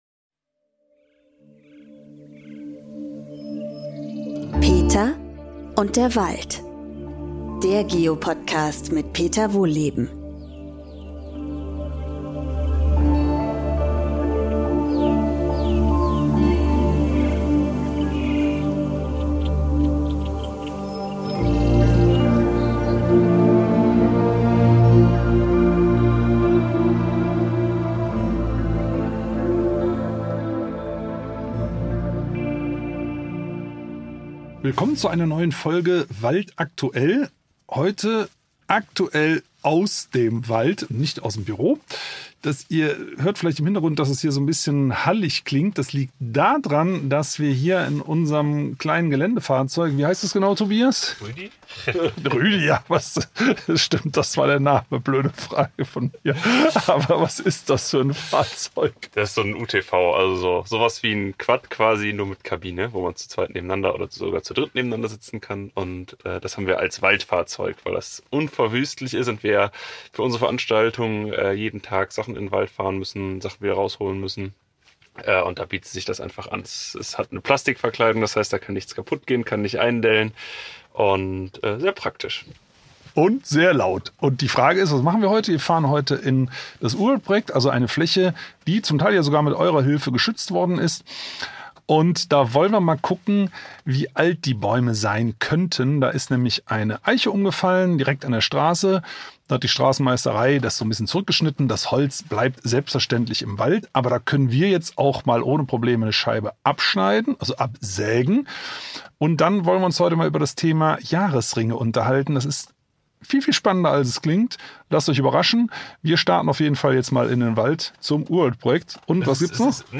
Aber nicht irgendwie, sondern mit einer Zweimannsäge.